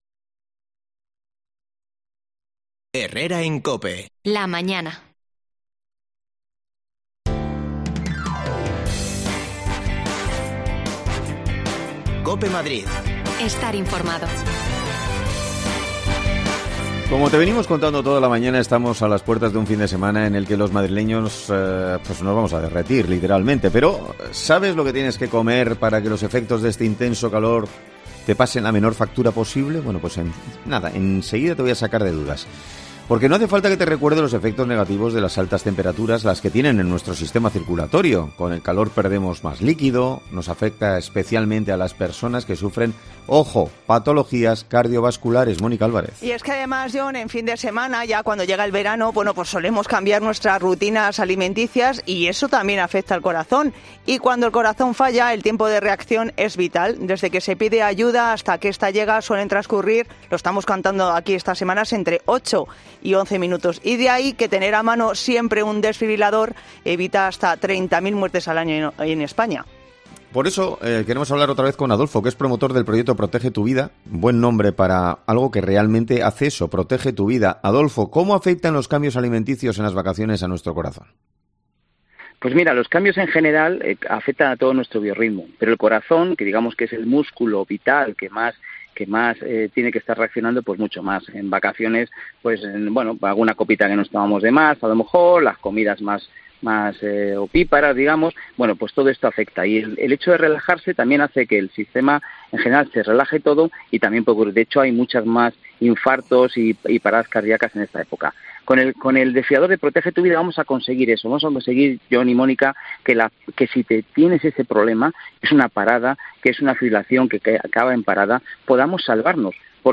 Te lo contamos con la ayuda de una nutricionista
Las desconexiones locales de Madrid son espacios de 10 minutos de duración que se emiten en COPE , de lunes a viernes.